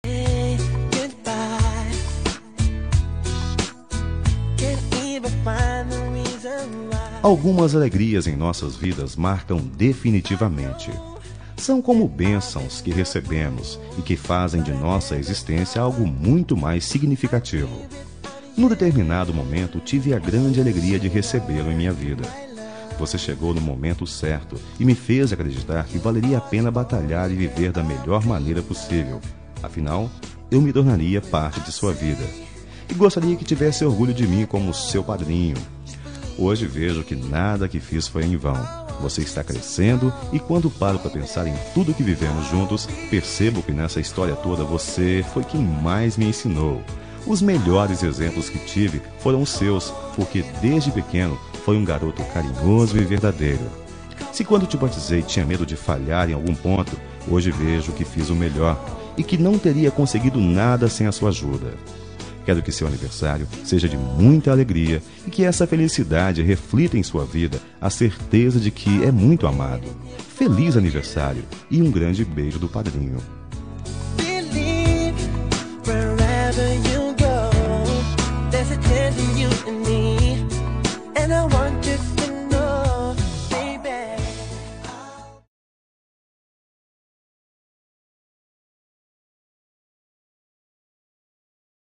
Aniversário de Afilhado – Voz Masculina – Cód: 2372